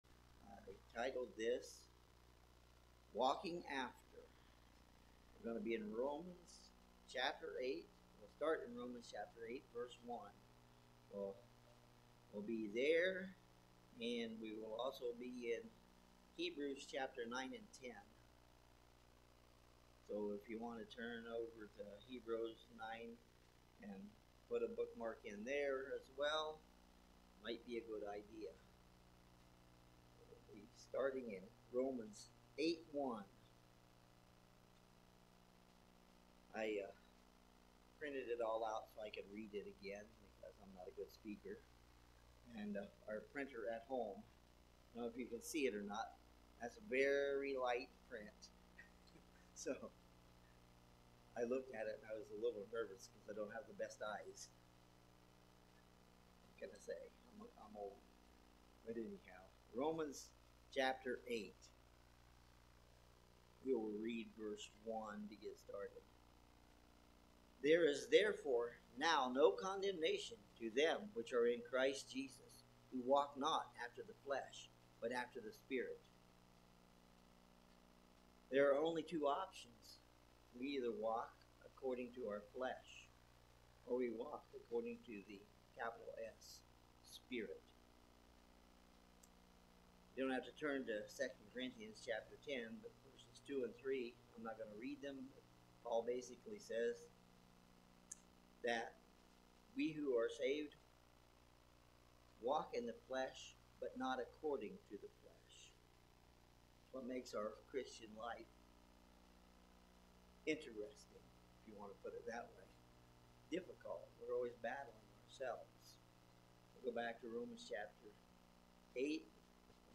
Wednesday-night-service.mp3